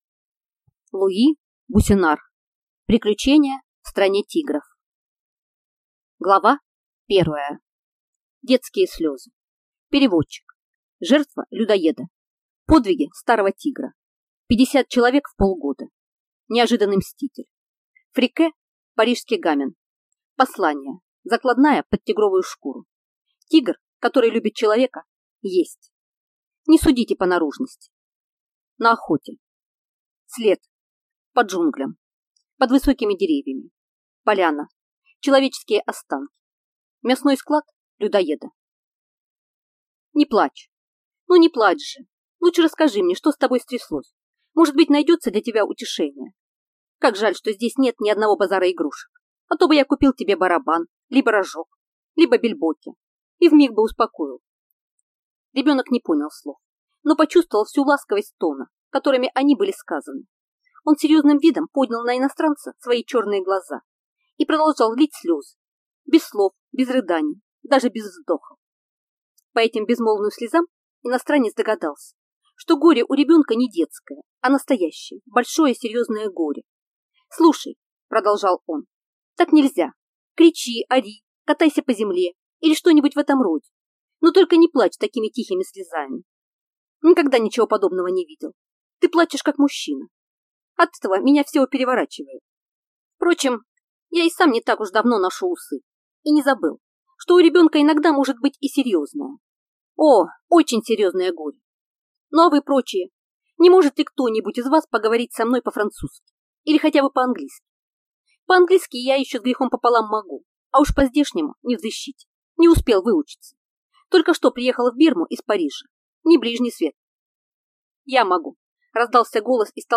Аудиокнига Приключения в стране тигров | Библиотека аудиокниг